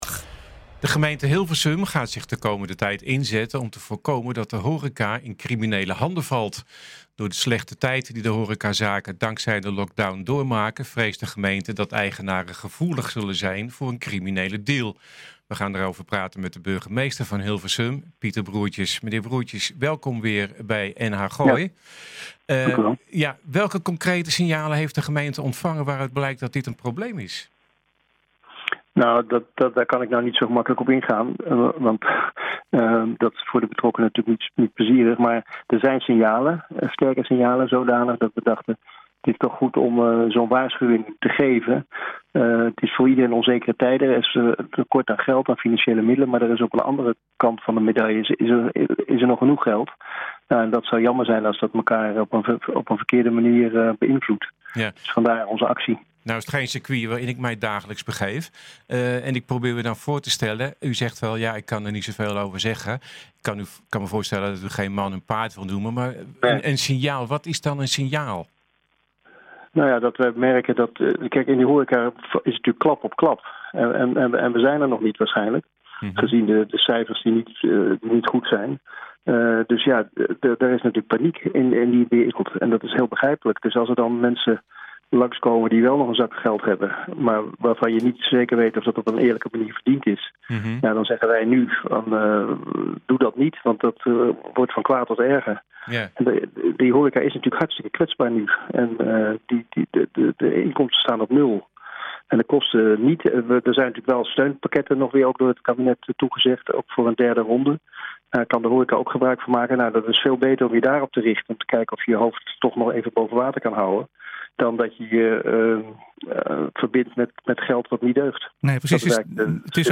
U luistert nu naar NH Gooi Zaterdag - Burgemeester Broertjes over coronacriminaliteit en coronasituatie regio
burgemeester-broertjes-over-coronacriminaliteit-en-coronasituatie-regio.mp3